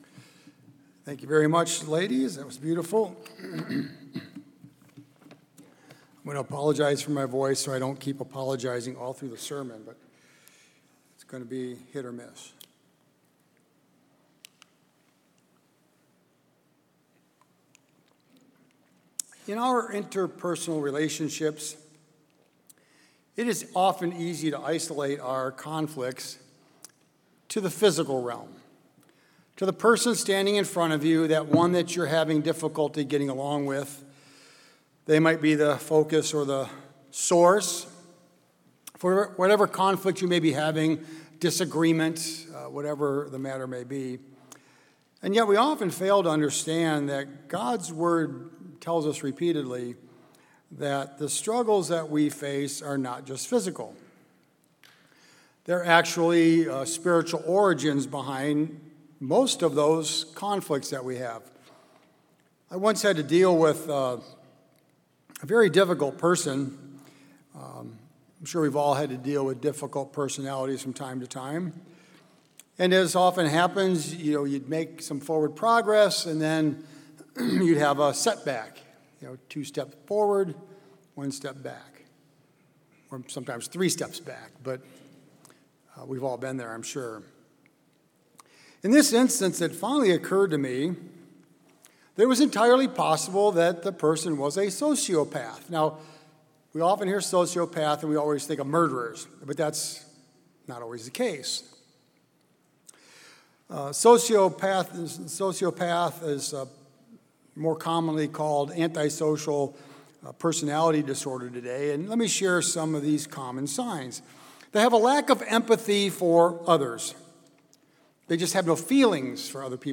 Sermons
Given in Columbus, OH